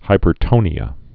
(hīpər-tōnē-ə)